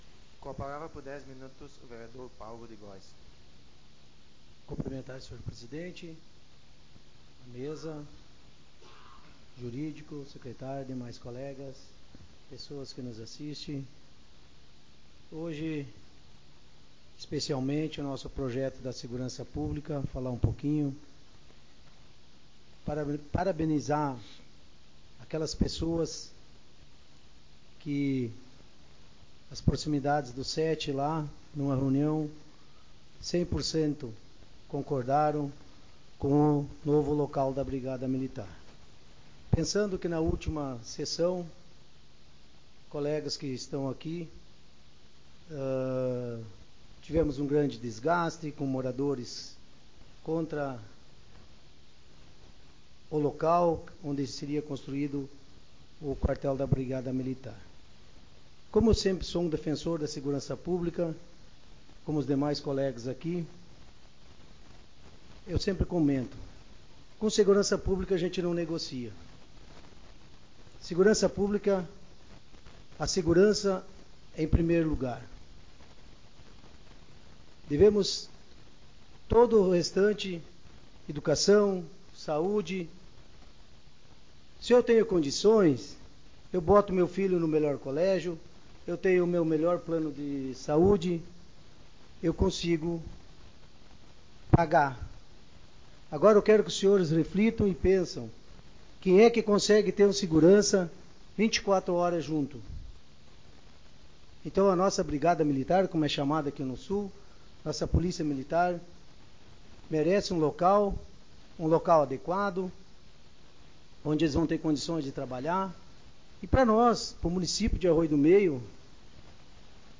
Modalidade: Áudio das Sessões Vereadores